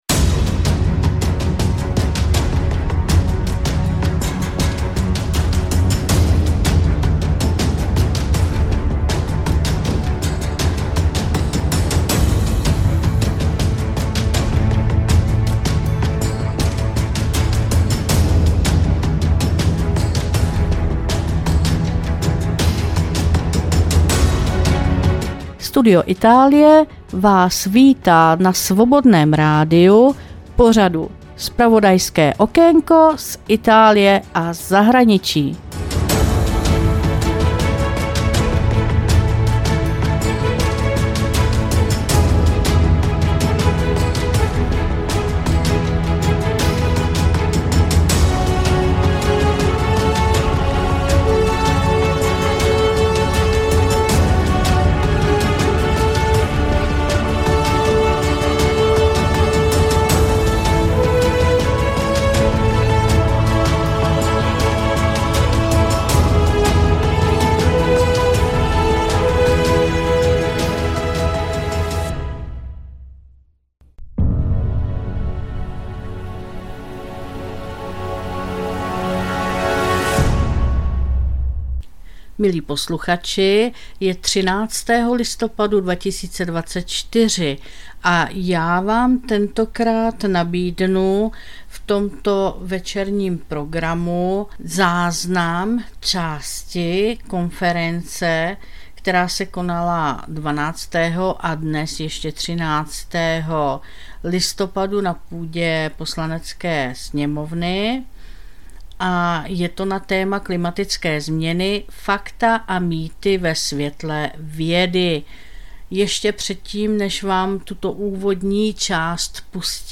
2024-11-13 – Studio Itálie – část. záznam z konference: Klimatické změny- fakta a mýty ve světle vědy